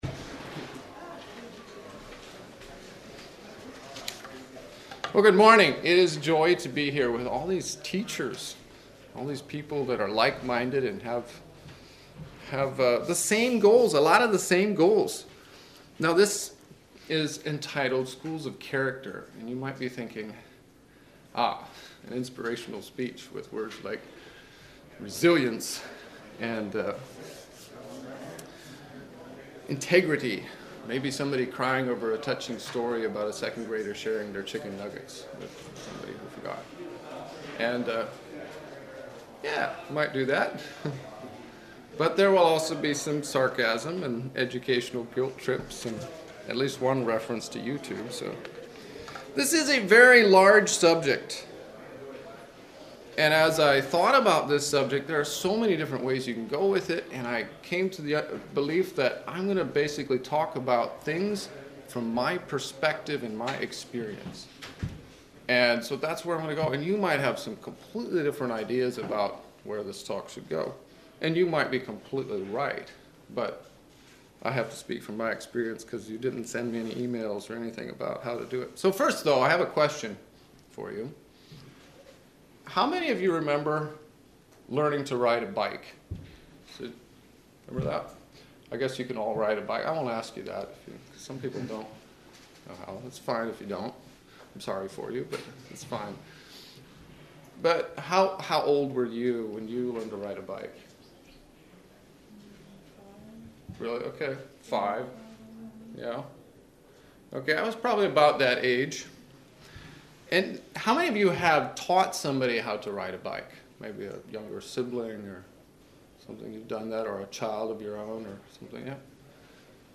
Midwest Teachers Week 2025 Recordings